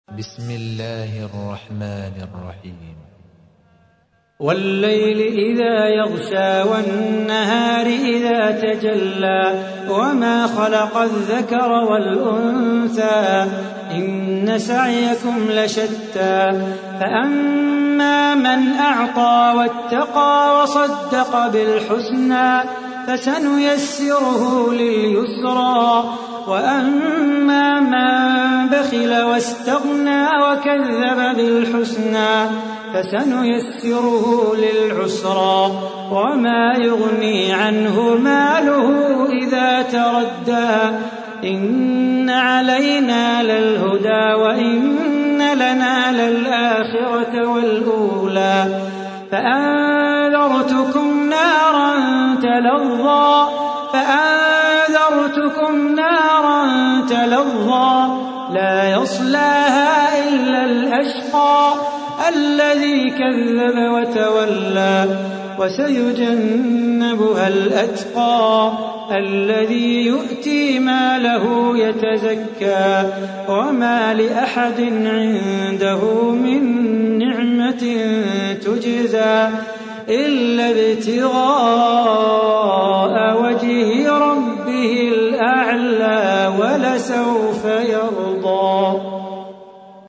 تحميل : 92. سورة الليل / القارئ صلاح بو خاطر / القرآن الكريم / موقع يا حسين